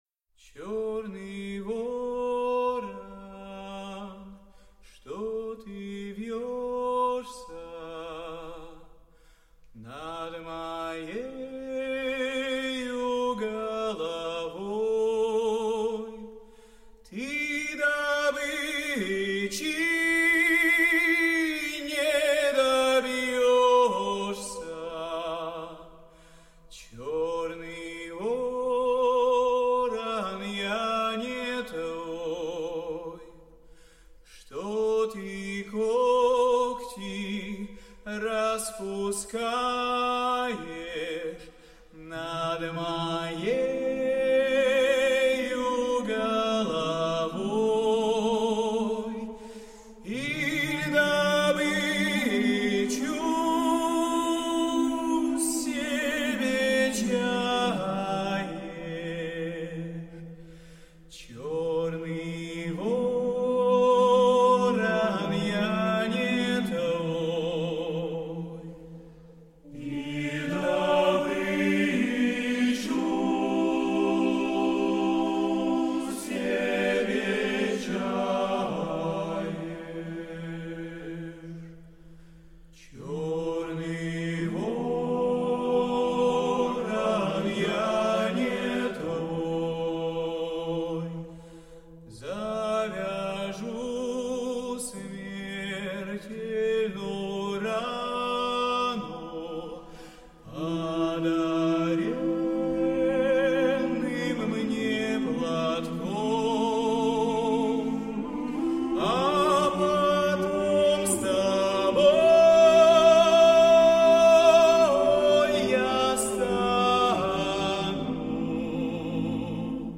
Мужской
Профессиональный вокалист (высокий баритон) и композитор-аранжировщик.
Певческий голос
Баритон